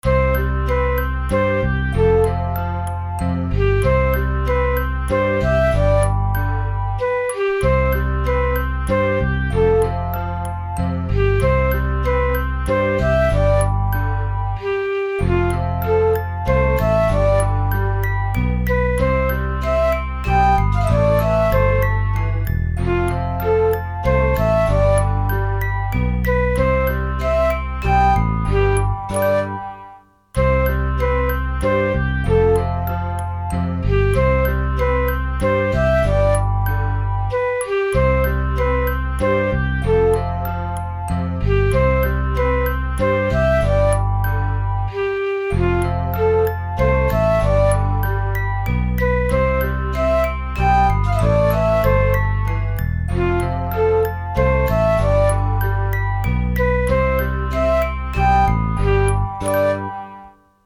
優しい・温かい
優しく和やかな感じ。BPMは遅め。
Bはドラム無し版です。